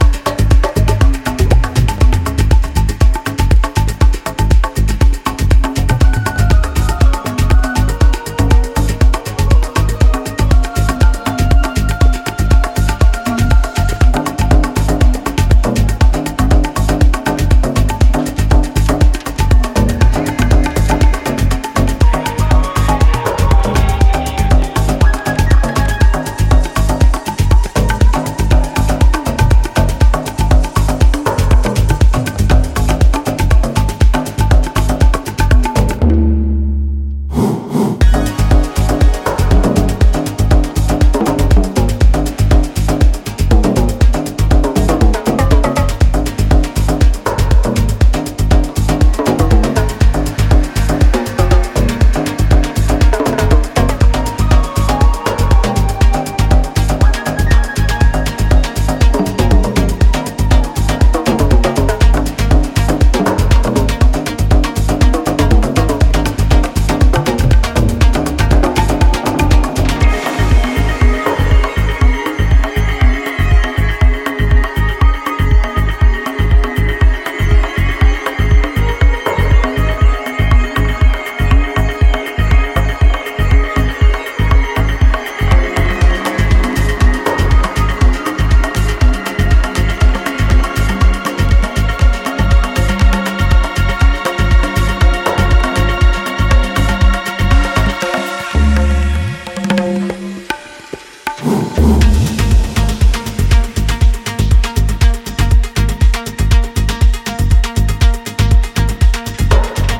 抜群の中毒性を放つリチュアル/トライバル・ハウス
Tabla